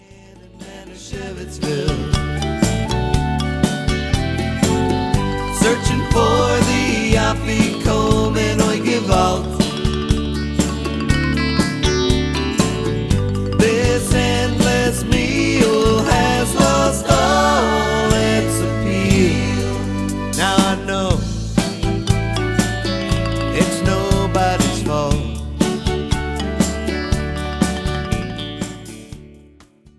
Hilarious cowboy and rock and roll parody songs.